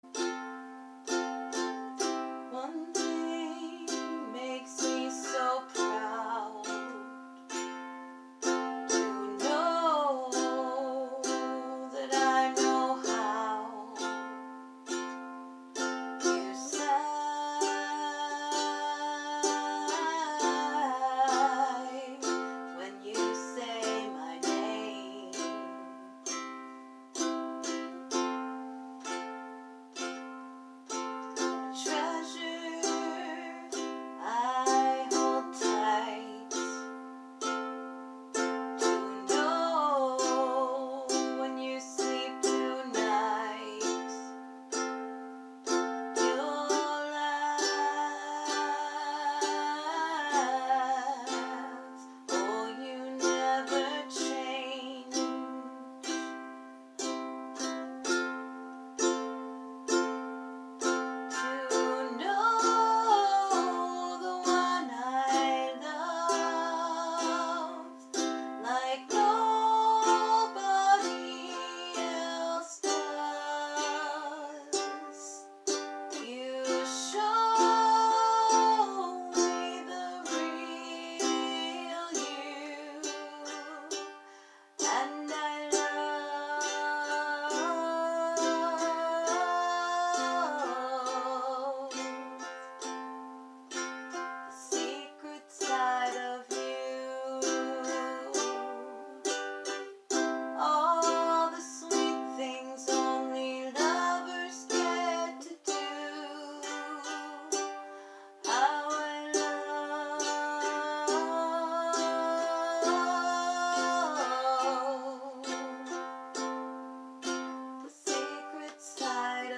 For my husband on our anniversary(he laughs in his sleep ;) <3 playing my ukulele and singing the first verse and chorus...